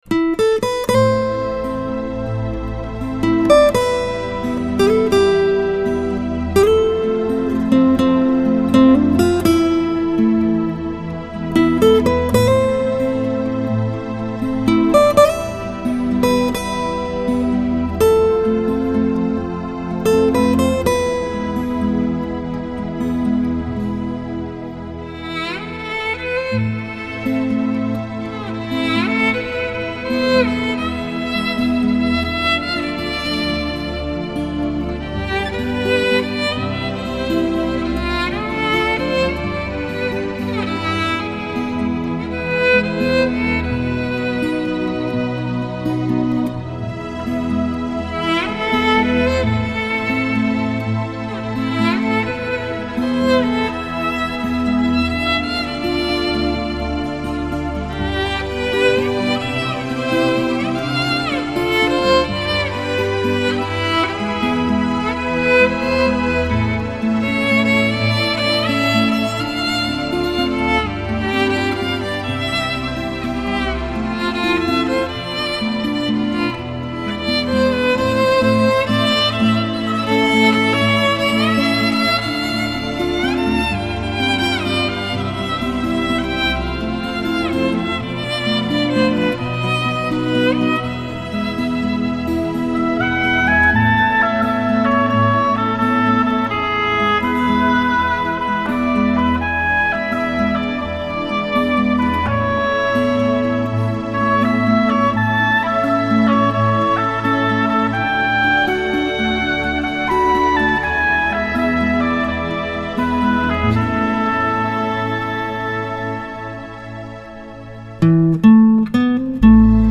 浪漫新世纪音乐